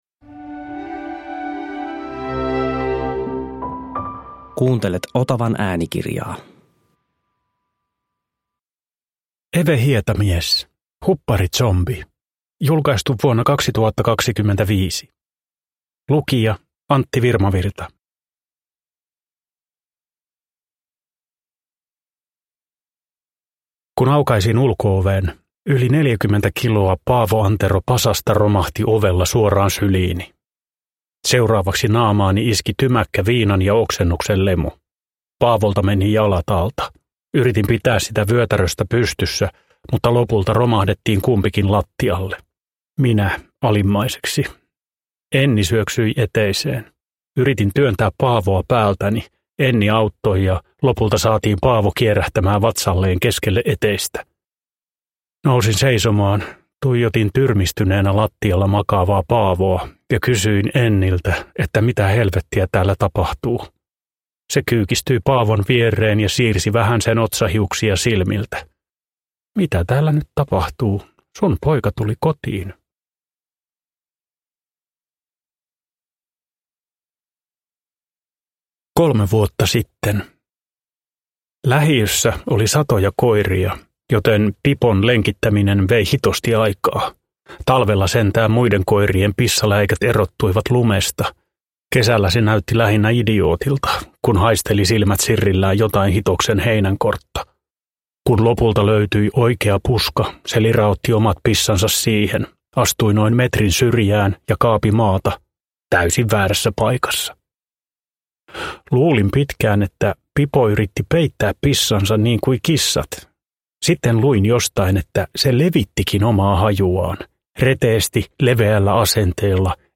Hupparizombi – Ljudbok